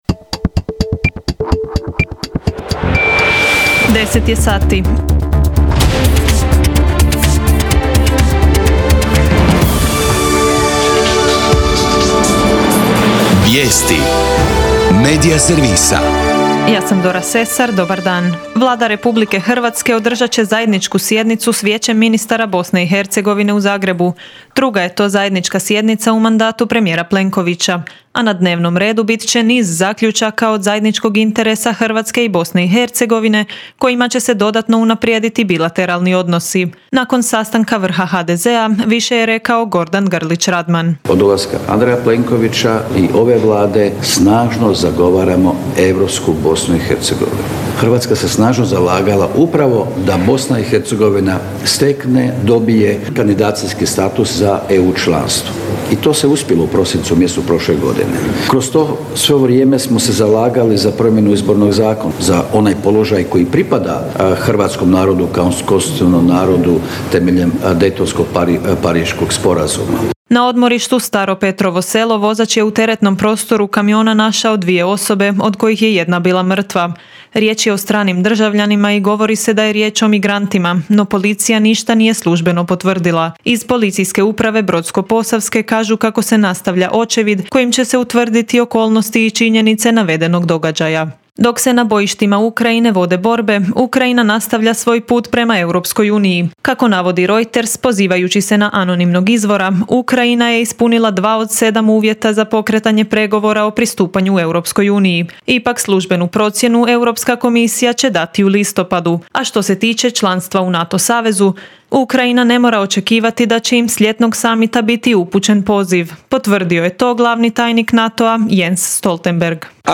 VIJESTI U 10